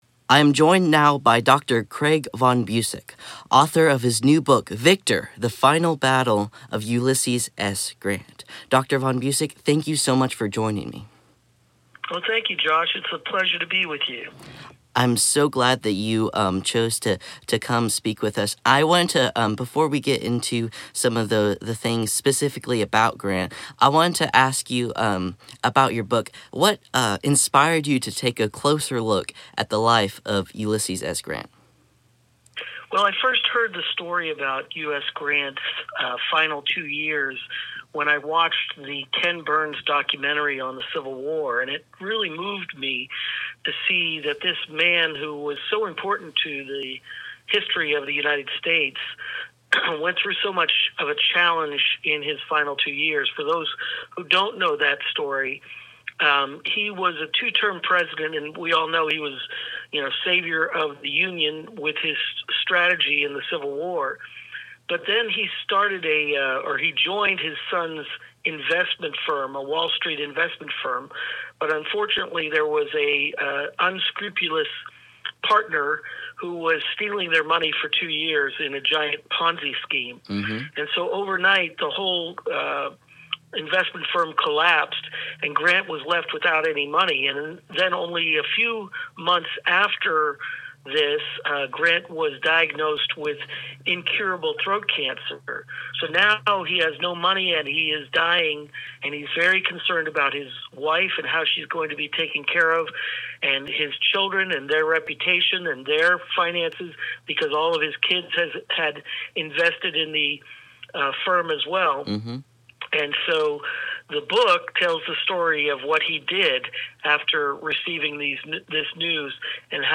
Answers Questions